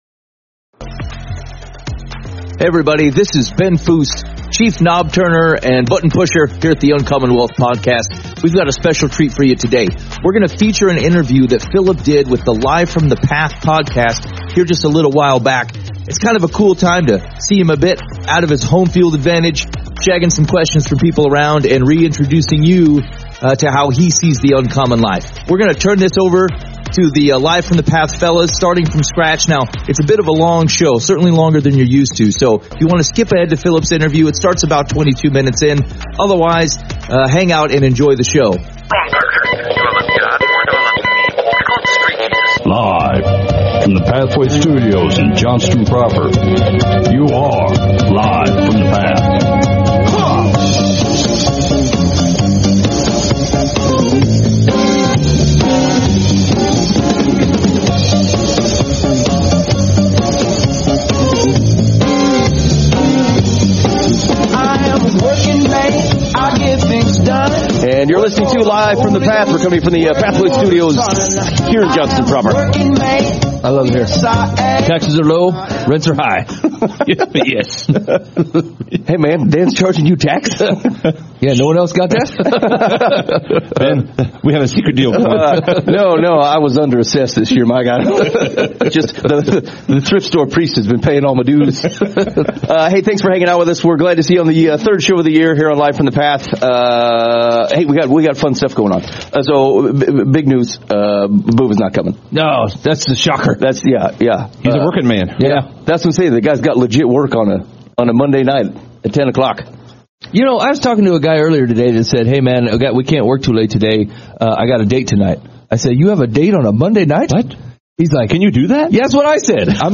Interview on Live From The Path